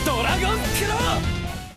Play, download and share Dragon_claw original sound button!!!!
dragon-claw.mp3